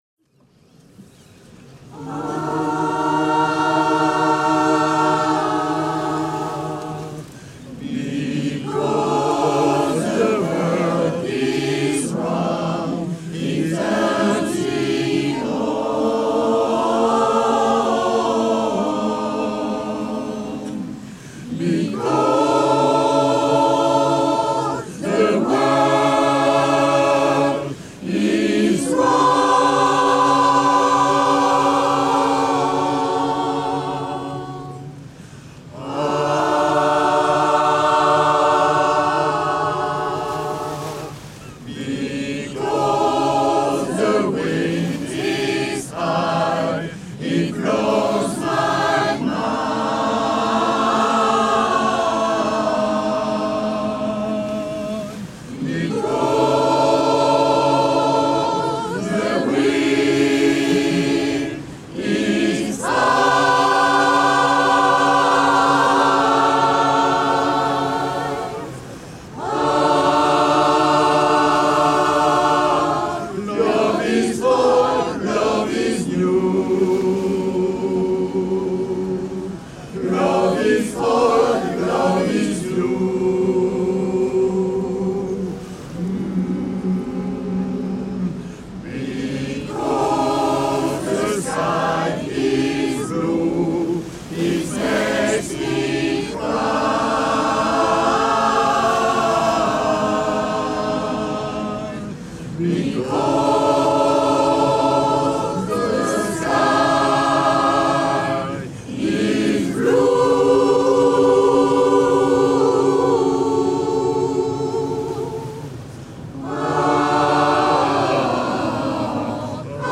– Chanter pour la mer du Nord
chorale-pour-la-mer-03-because.mp3